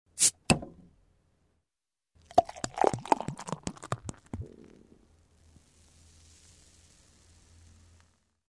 Звуки открытия пива
Звук открывания пива и наливания